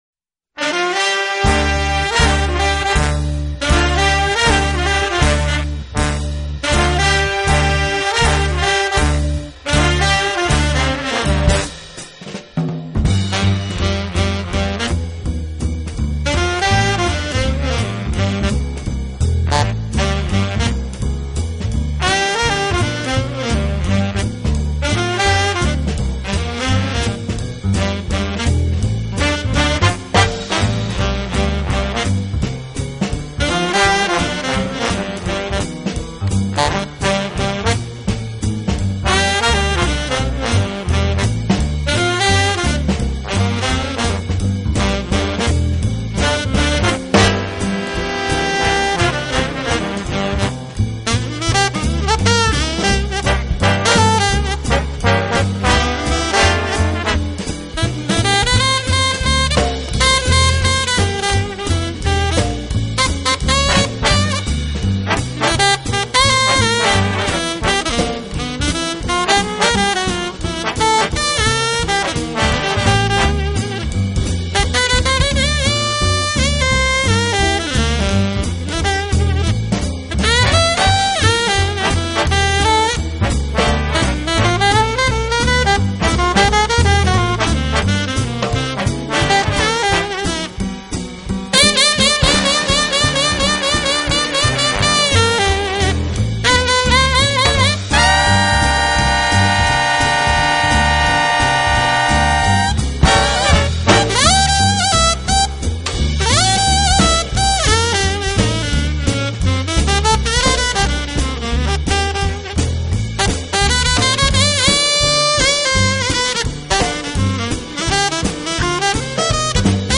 音乐类型：Jazz